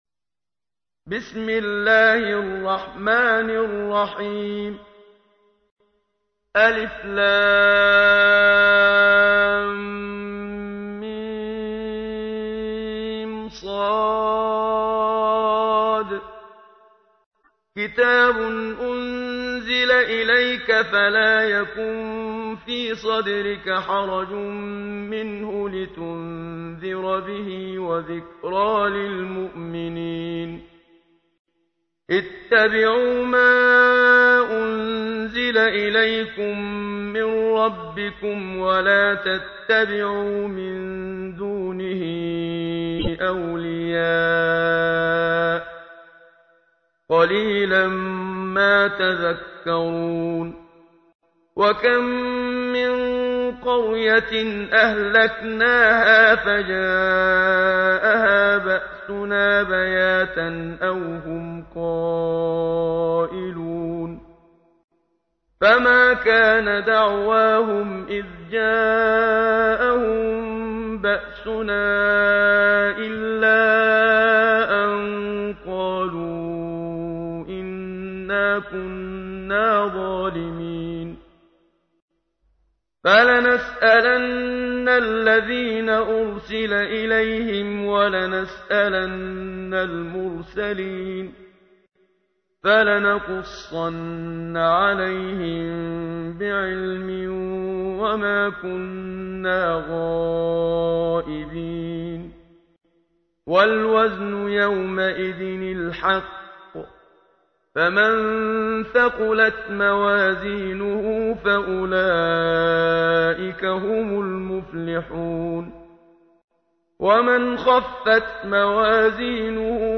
تحميل : 7. سورة الأعراف / القارئ محمد صديق المنشاوي / القرآن الكريم / موقع يا حسين